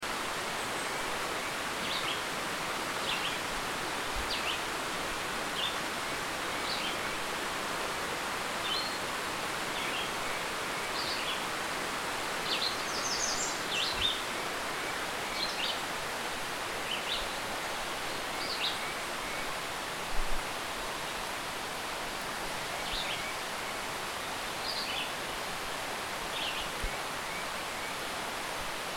Výběr zvuků od východního pobřeží Massachusetts do Zelených hor ve Vermontu.